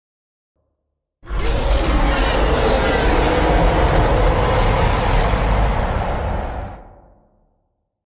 Звуки Халка
Скачивайте или слушайте онлайн его легендарный рык, яростные крики, разрушительные удары и угрожающее дыхание.